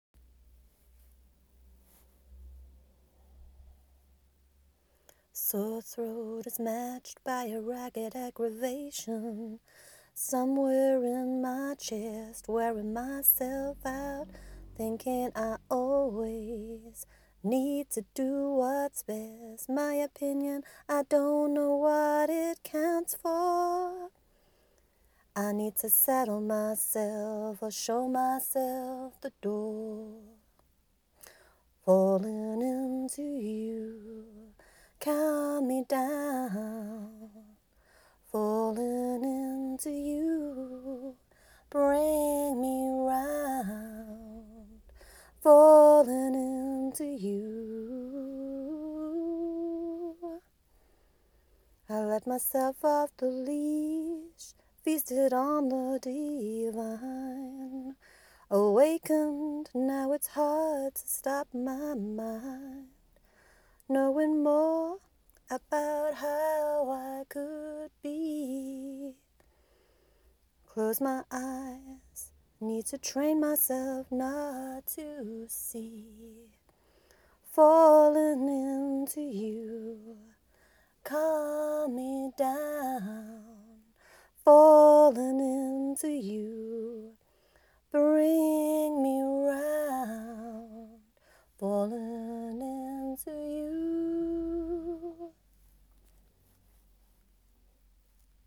I recorded this in a whim without planning the tune. It was done in one take in a car park - no take two as car park became full and I'll leave it here, it's not doing any harm and it shows it could be better with a bit of effort